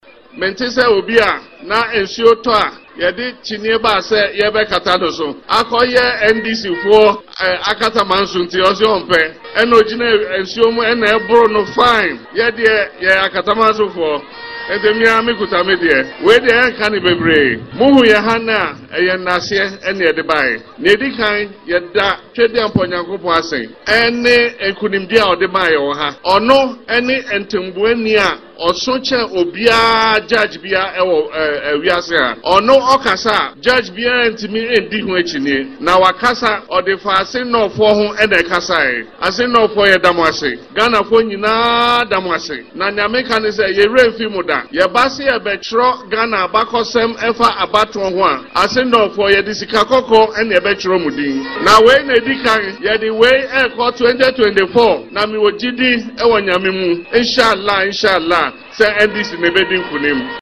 Speaking during the ‘Thank You Tour’ following the victory of James Gyakye Quayson in the by-election on June 27, Mahama acknowledged the significance of Assin North in Ghana’s history and expressed confidence in the NDC’s chances of recapturing power in the upcoming elections.